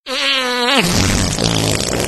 fart.mp3